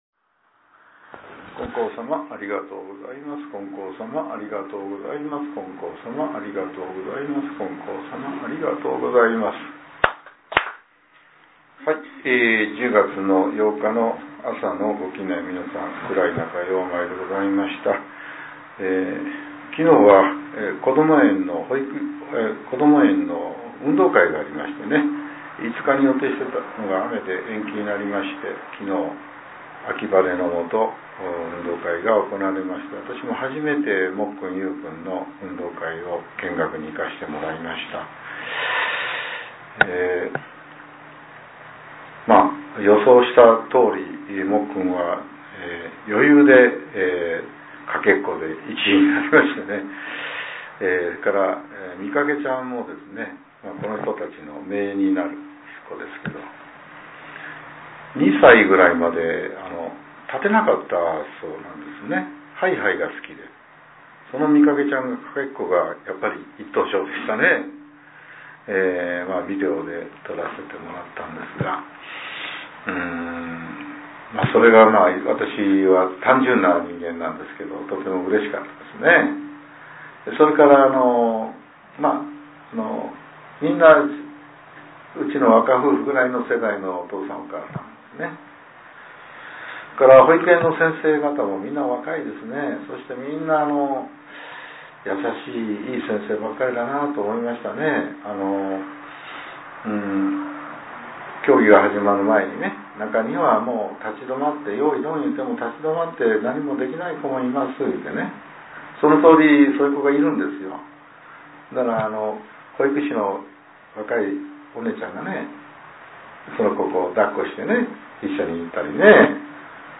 令和７年１０月８日（朝）のお話が、音声ブログとして更新させれています。